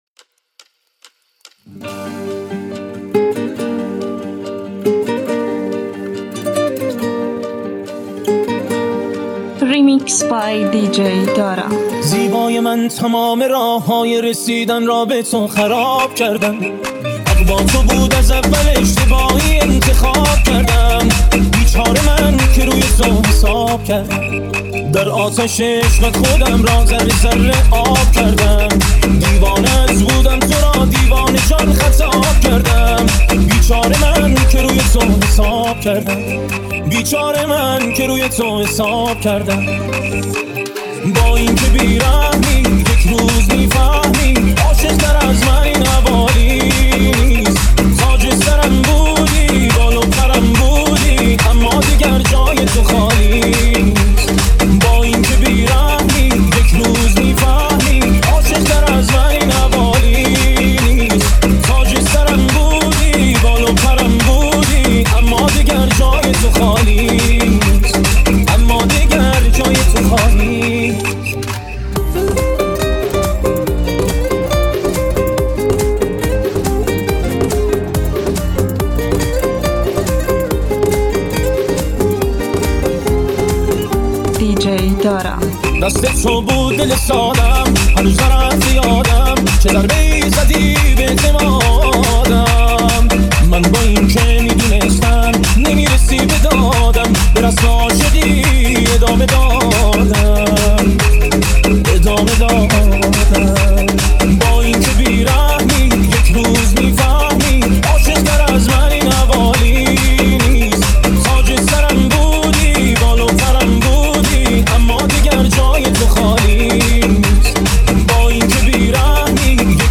ریمیکس سوت دار